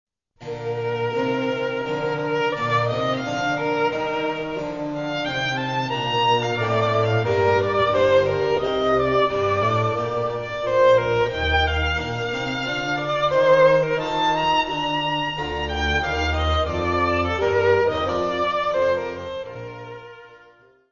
: stereo; 12 cm
Área:  Música Clássica